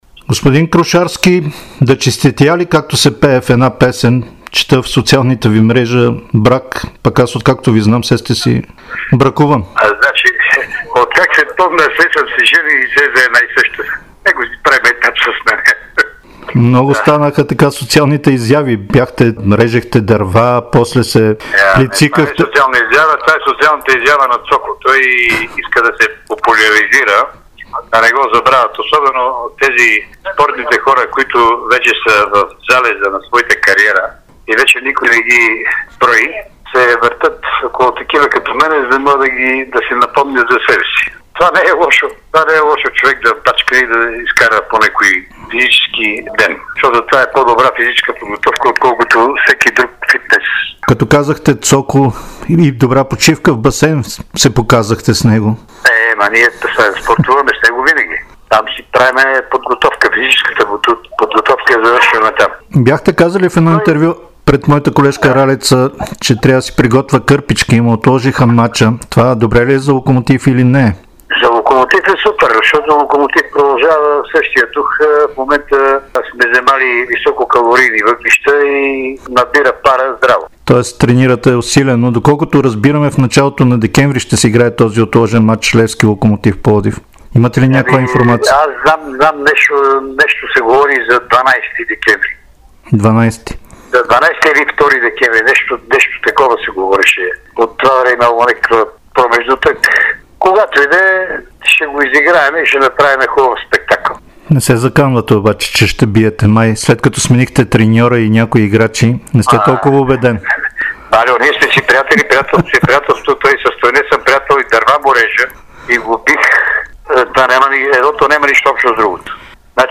специално интервю за Дарик радио и dsport